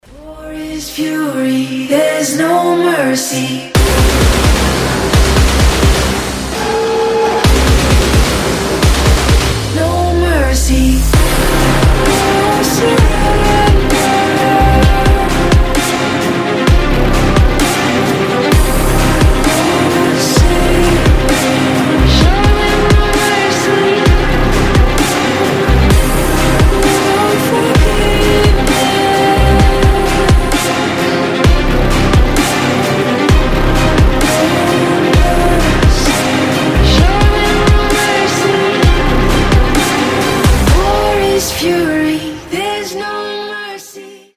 громкие
красивый женский голос
оркестр
эпичные
Neoclassical